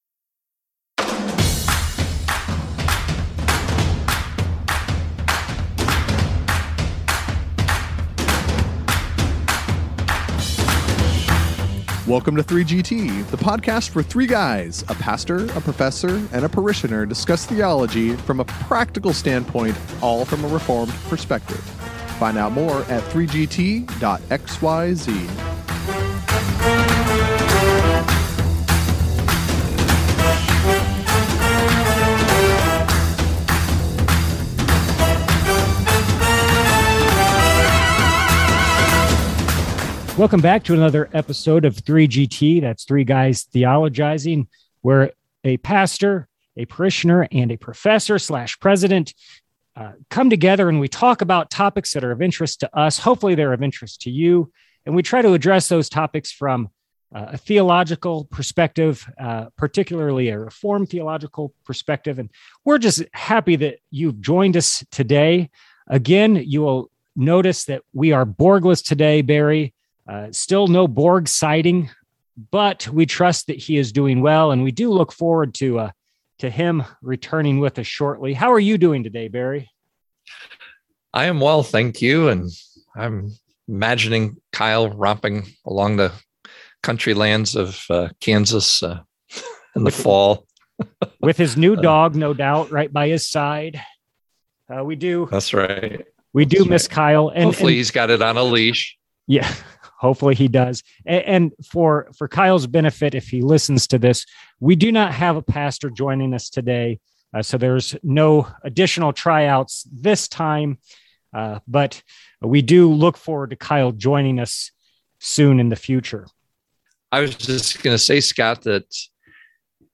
The parishioner and the professor decide to take a virtual walk down the driveway to the mailbox and see what it contains. They read excerpts and respond to questions and issues 3GT listeners have sent in.